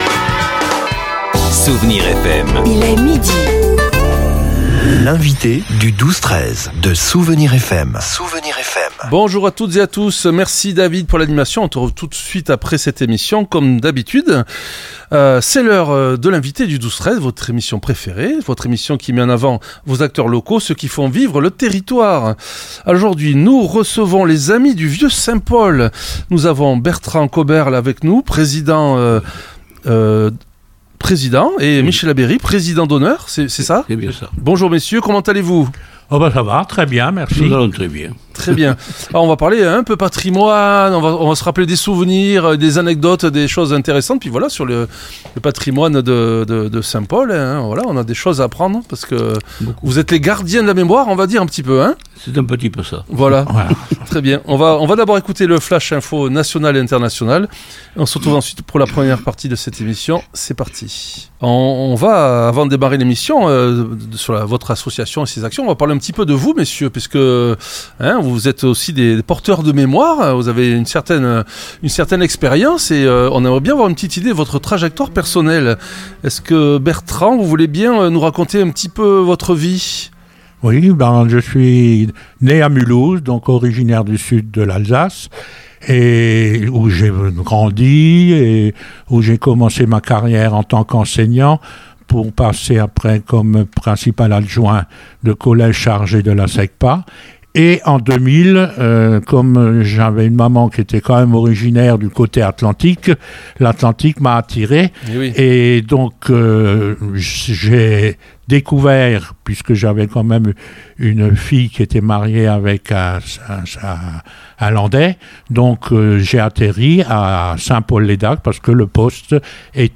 L'entretien a mis en lumière un projet d'envergure soutenu par le Budget Participatif Citoyen des Landes : la réhabilitation du verger du Domaine d’Abesse. En s'appuyant sur le tissus associatif local, l'association redonne vie à des variétés fruitières des années 1920, créant ainsi un pont vivant entre l'agriculture d'antan et les enjeux écologiques actuels.